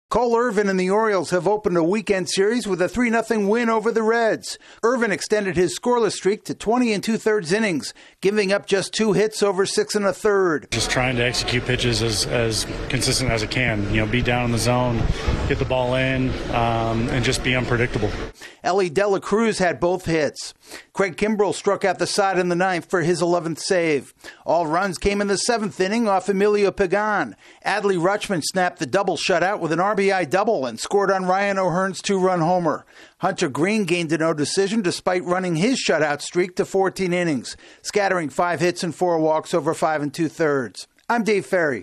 The Orioles get another outstanding pitching performance for the sixth consecutive game. AP correspondent